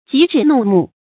戟指怒目 注音： ㄐㄧˇ ㄓㄧˇ ㄋㄨˋ ㄇㄨˋ 讀音讀法： 意思解釋： 指著人，瞪著眼。